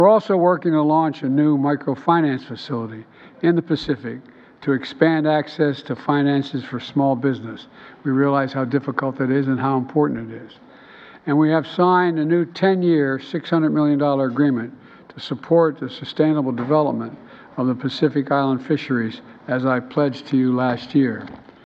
US President Joe Biden.